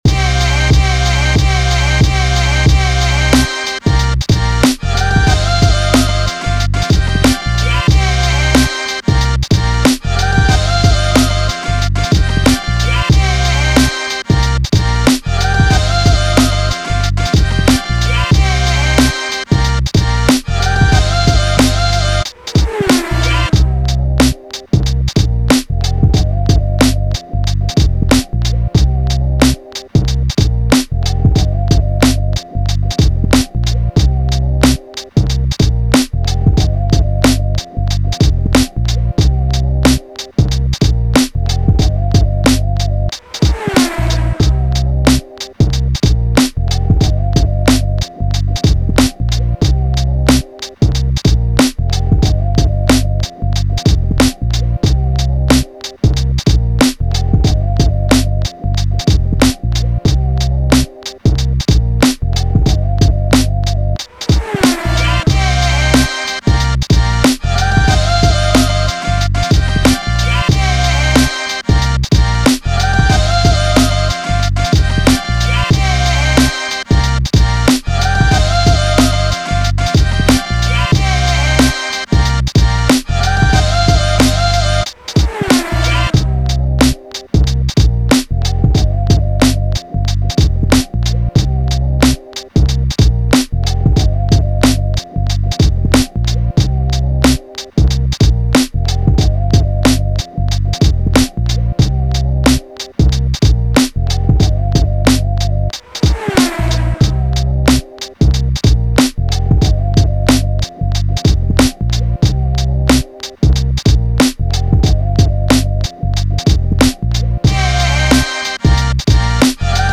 Hip Hop, 90s
Cm